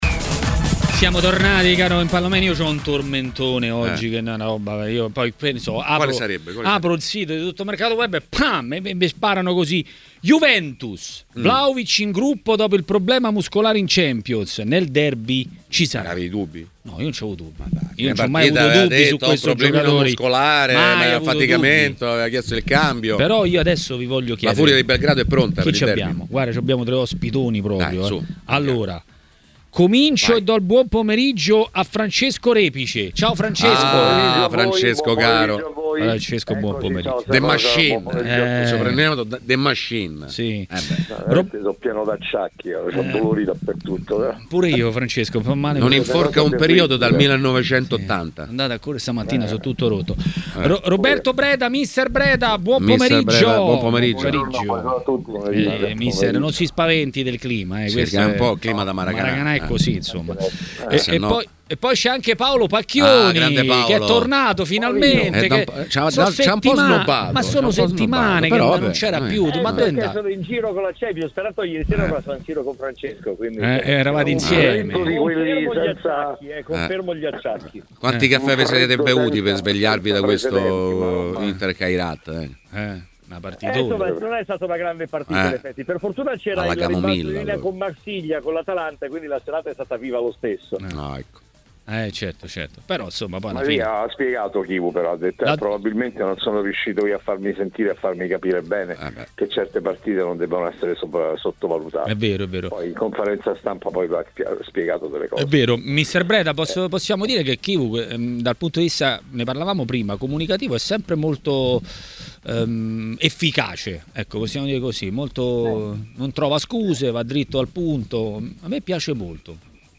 Ospite di TMW Radio, durante Maracanà